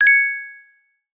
click-sound.mp3